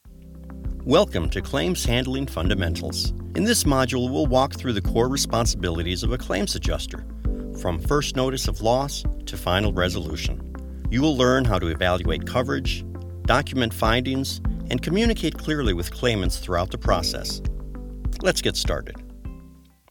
His delivery is warm, steady, and approachable, making even complex material feel manageable.
E- Learning Demos